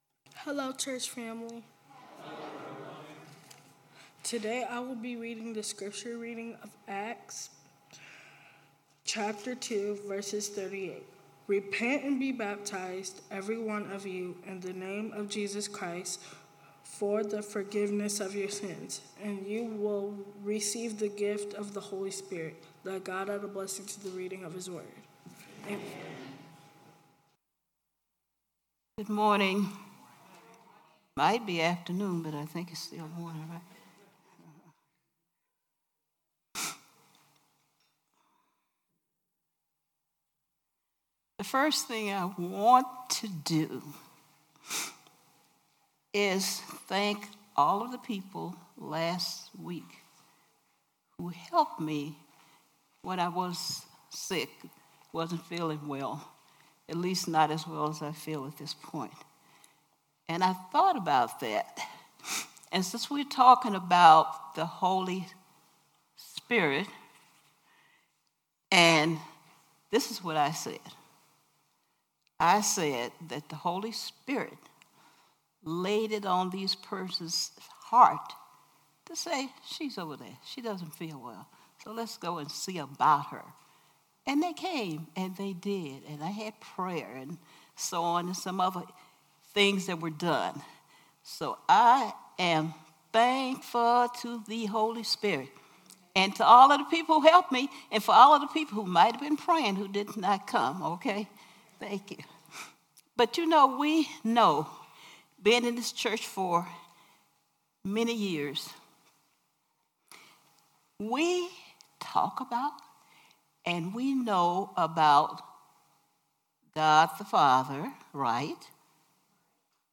Worship Service 4/15/18